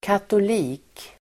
Ladda ner uttalet
Folkets service: katoliker katolik substantiv, Catholic Uttal: [katol'i:k] Böjningar: katoliken, katoliker Definition: person som tillhör katolska kyrkan katoliker substantiv, catholics catholics substantiv, katoliker